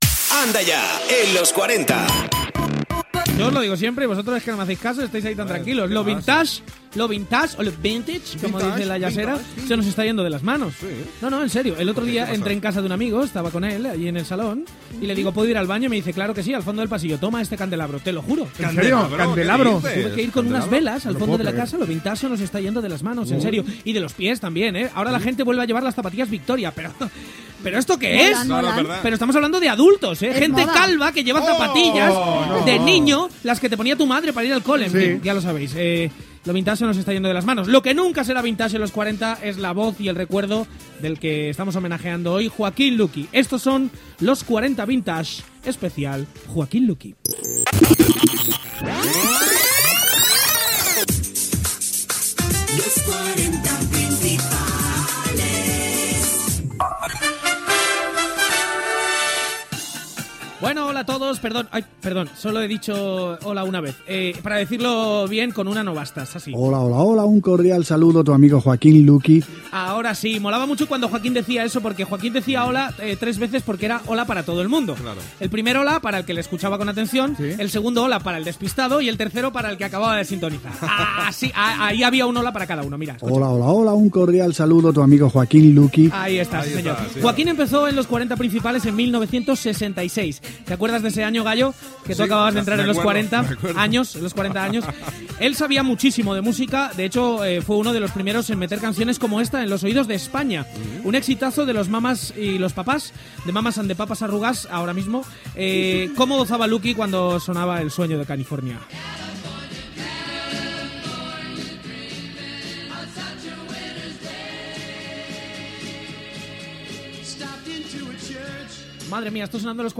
Indicatiu de la ràdio.
Entreteniment
FM